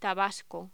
Locución: Tabasco
voz